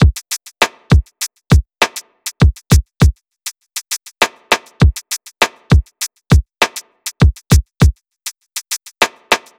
TI100BEAT1-L.wav